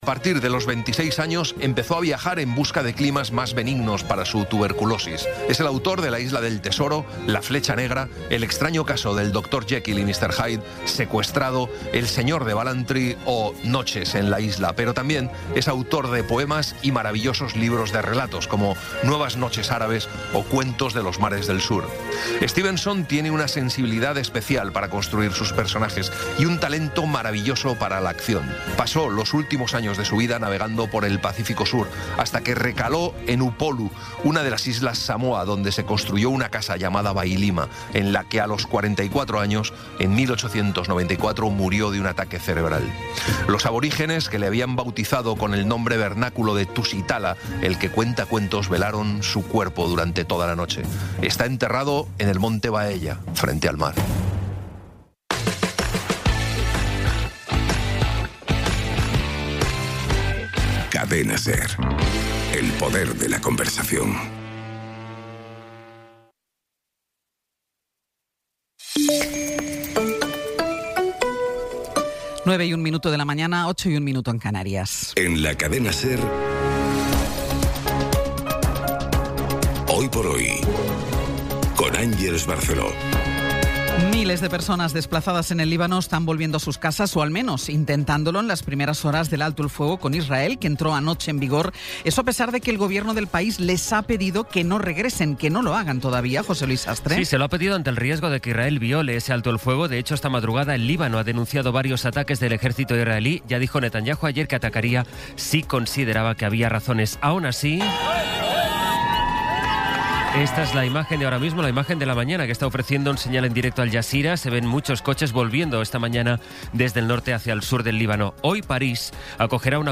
Resumen informativo con las noticias más destacadas del 17 de abril de 2026 a las nueve de la mañana.